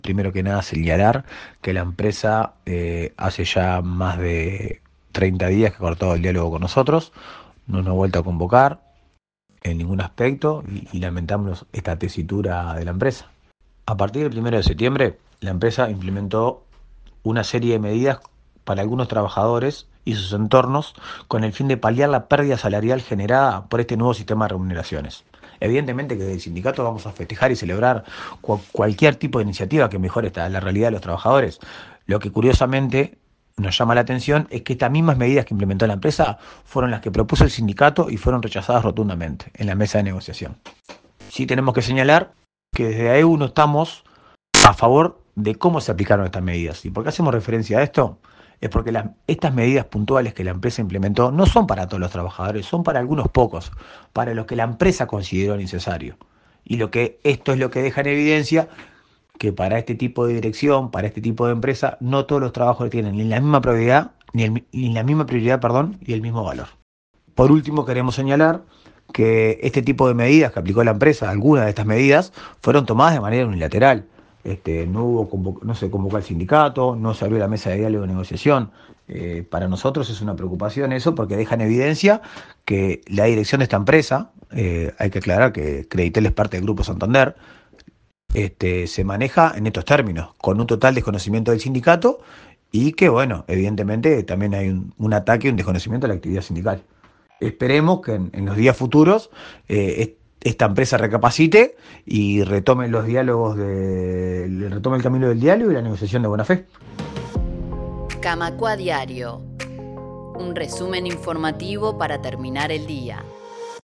explicó en diálogo con Camacuá y Reconquista.